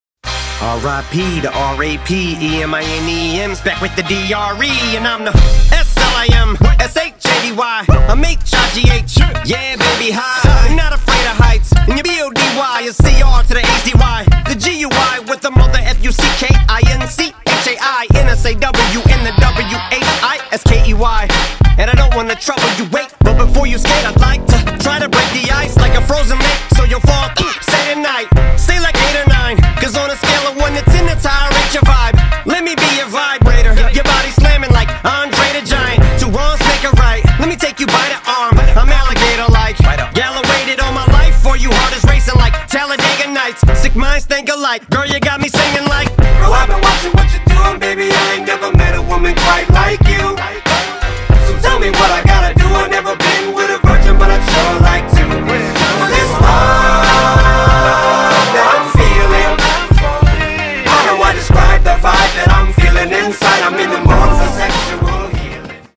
• Качество: 320, Stereo
качающие
Gangsta rap
зарубежный рэп